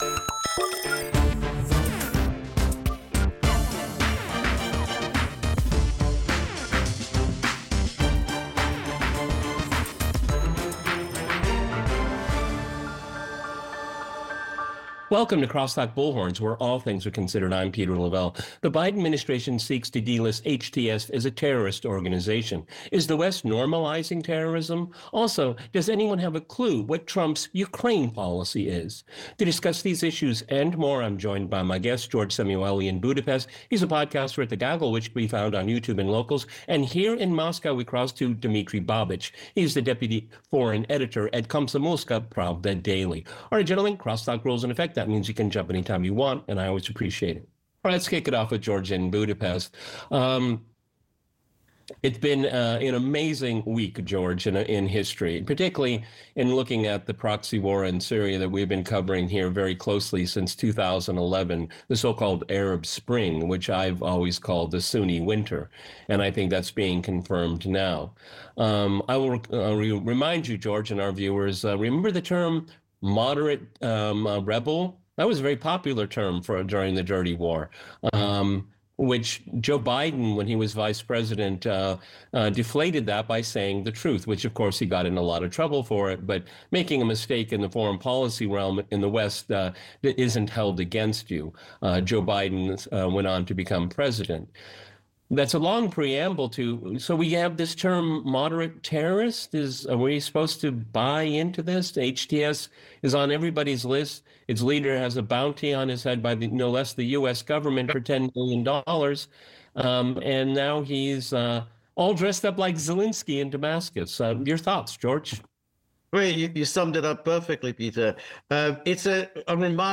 Now you know the way to Putin’s office! Sneak a peek as he walks to inauguration in Kremlin (VIDEO)